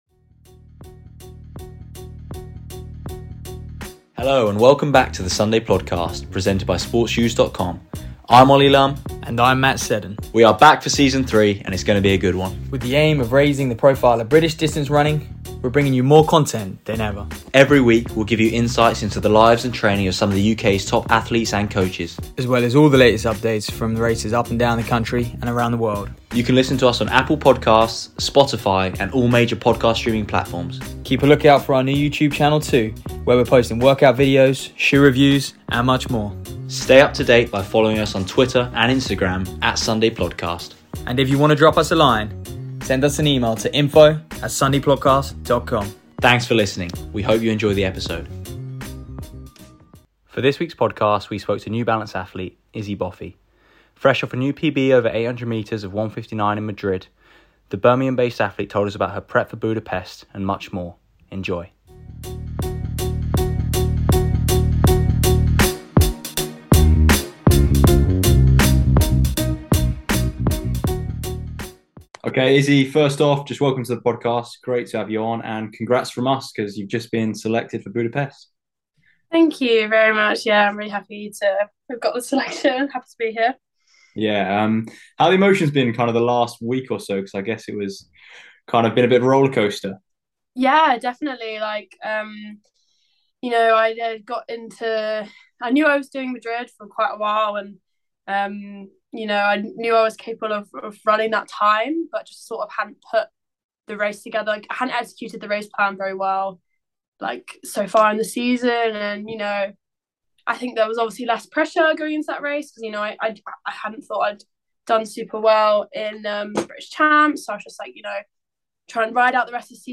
For this week's podcast, we spoke to New Balance athlete, Issy Boffey. The Birmingham-based athlete spoke to us about her new PB of 1:59 over 800m in Madrid, as well as her preparations for the World Champs in Budapest, and much more.